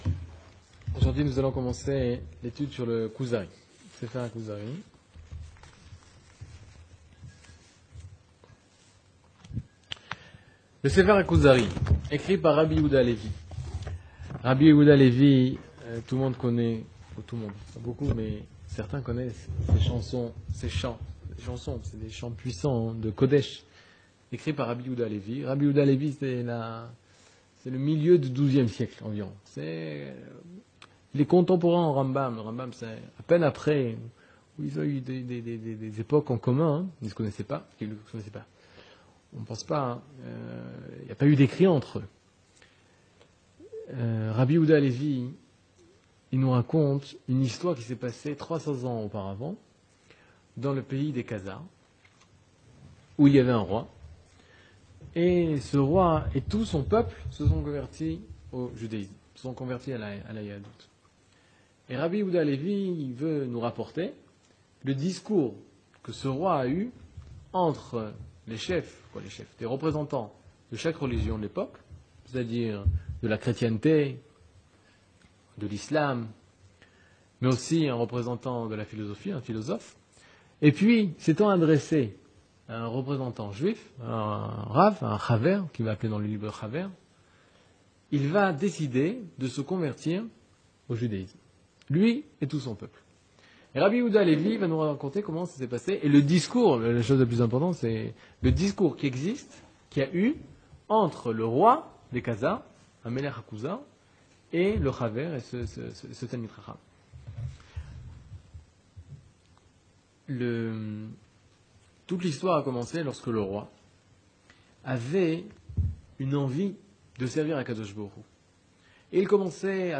Mini-cours